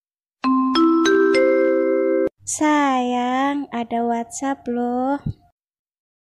Genre: Nada dering imut